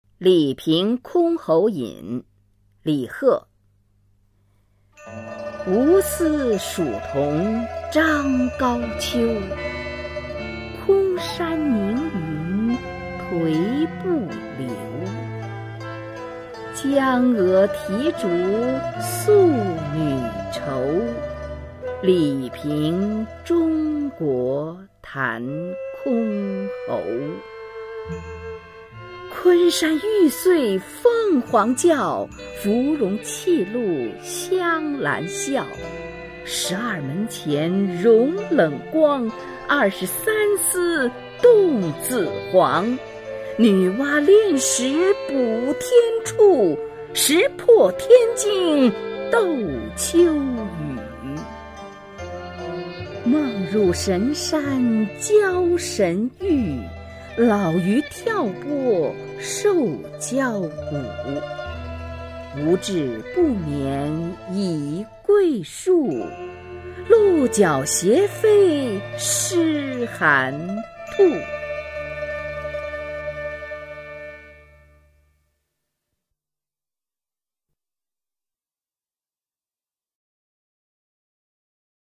[隋唐诗词诵读]李贺-李凭箜篌引 配乐诗朗诵